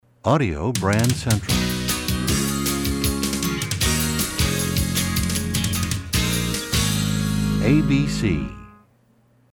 MCM Category: Radio Jingles
Genre: Jingles.